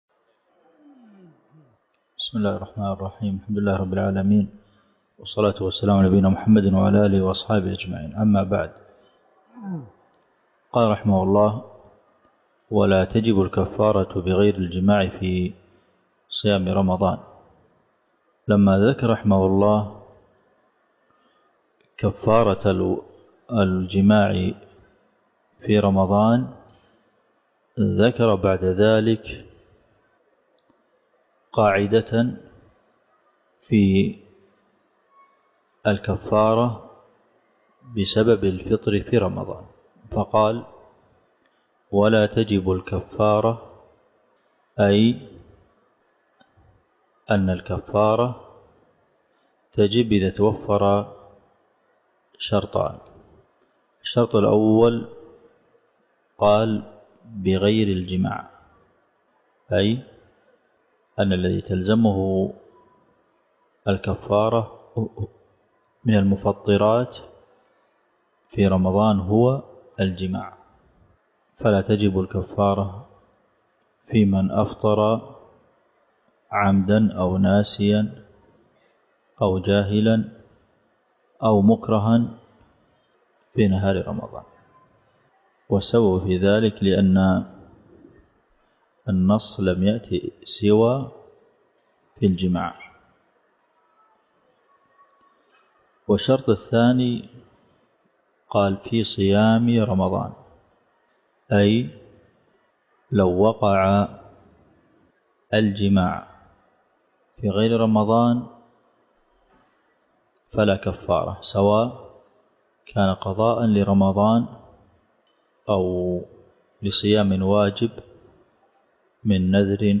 الدرس (11) شرح زاد المستقنع دورة في فقه الصيام - الشيخ عبد المحسن القاسم